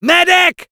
Voice file from Team Fortress 2 French version.